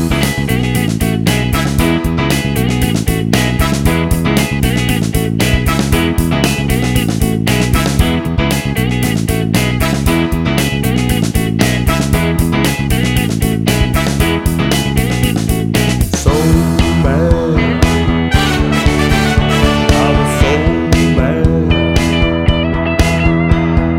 No Drums Or Percussion Soundtracks 3:05 Buy £1.50